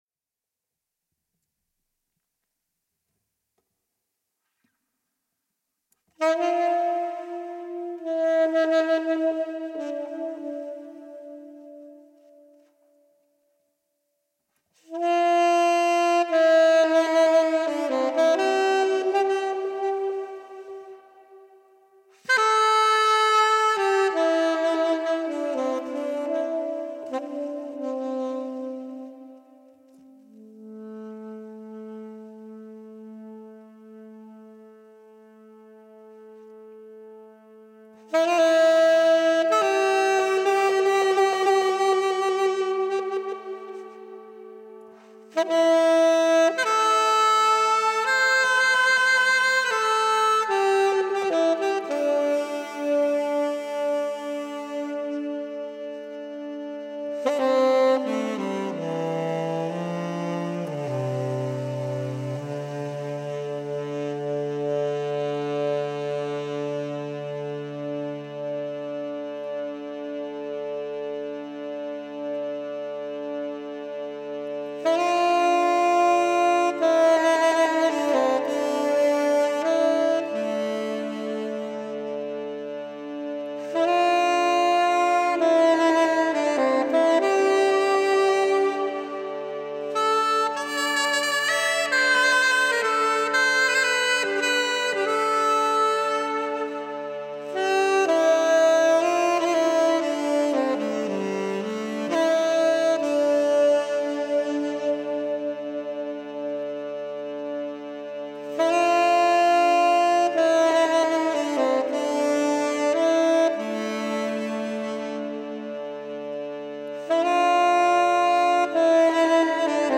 ingetogen